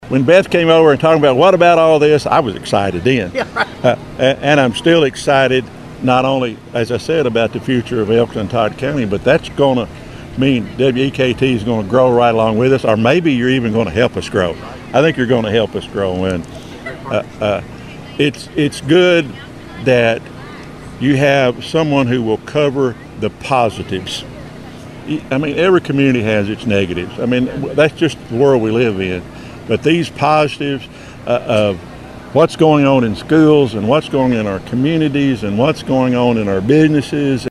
Mayor Green told WEKT News during the recent Todd County Harvest Festival there is an exciting forward momentum in Elkton and Todd County.